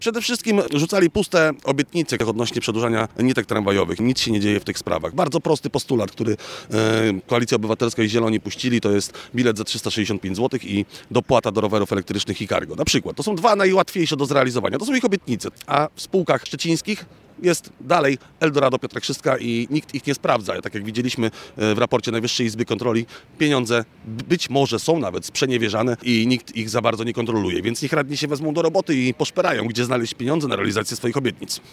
Rozdawanie pączków i przyozdabianie świątecznego tramwaju zamiast realnej pracy na rzecz mieszkańców – takie zarzuty wobec radnych Koalicji Obywatelskiej w szczecińskiej Radzie Miasta stawiają politycy Konfederacji. Podczas dzisiejszej konferencji prasowej